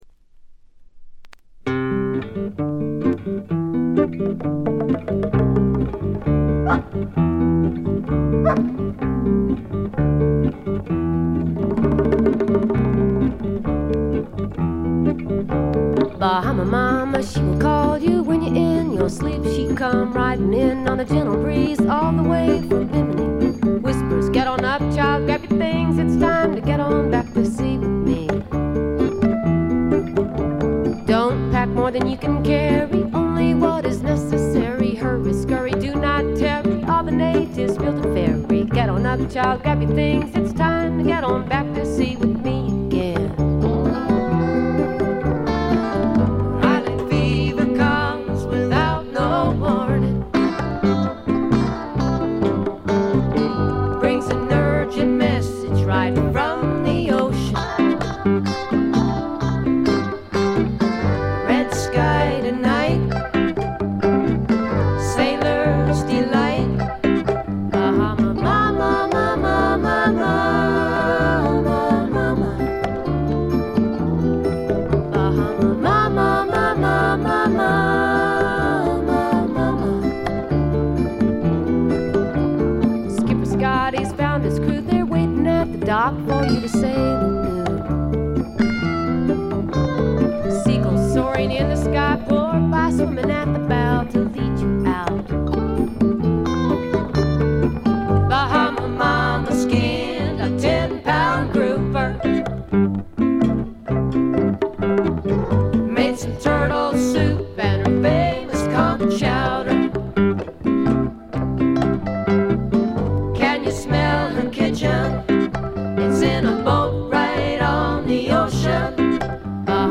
軽微なチリプチ少々。
フォーキーな女性シンガーソングライター作品の大名盤です！
試聴曲は現品からの取り込み音源です。